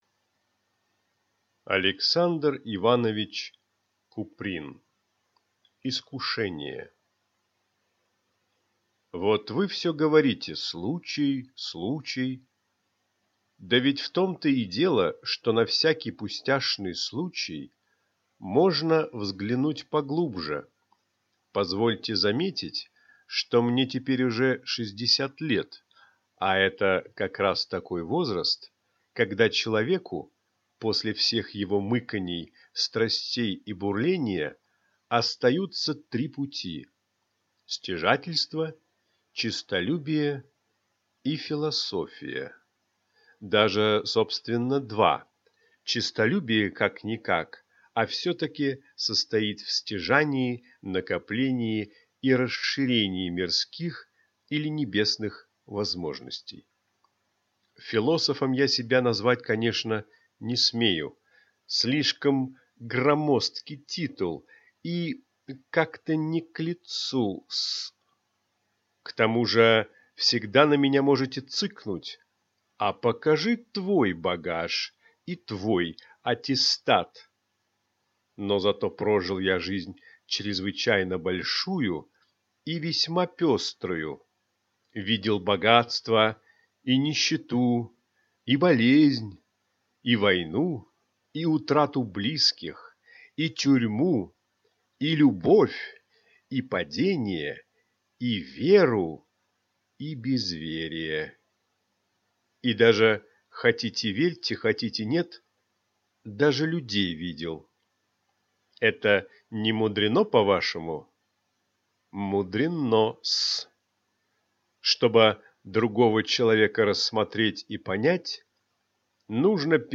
Аудиокнига Искушение | Библиотека аудиокниг
Прослушать и бесплатно скачать фрагмент аудиокниги